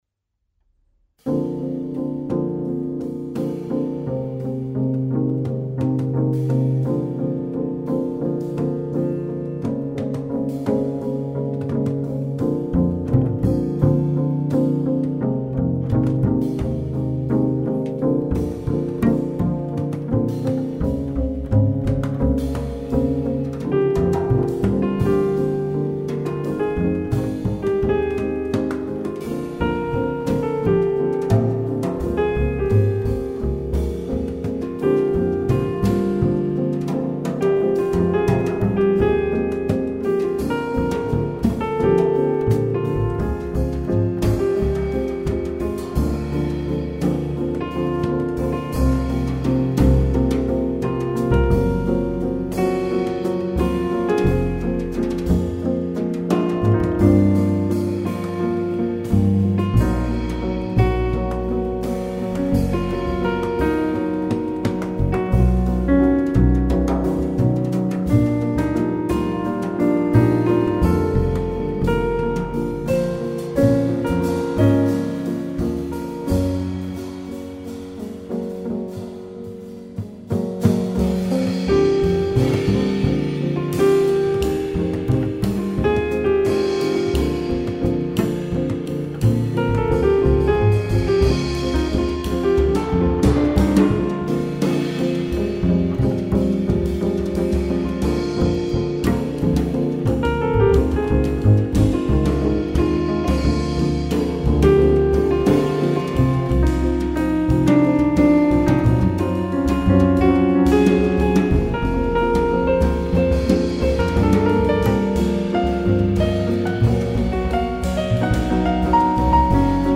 Contemporary.
pianist